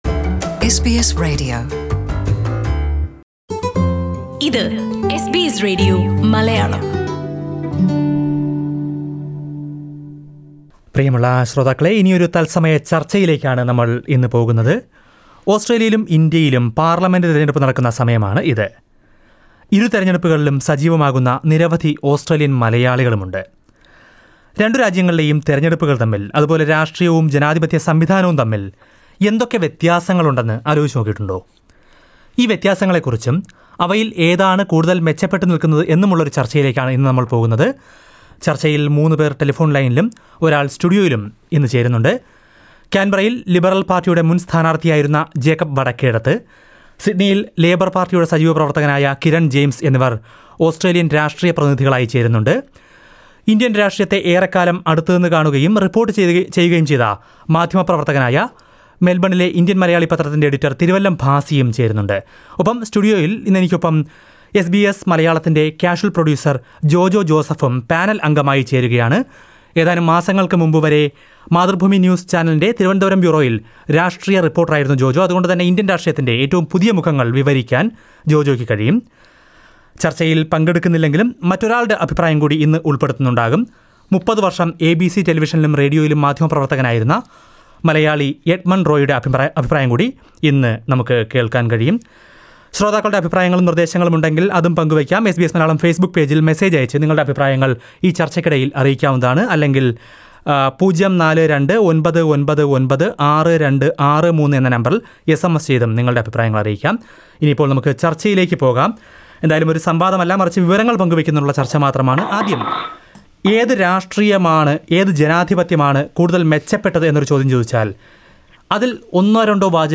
SBS discussion: the differences between Australian democracy and Indian democracy
While both Australia and India are going to the polls, many of us tend to compare both the democracies. Here is a discussion on the pros and cons of both.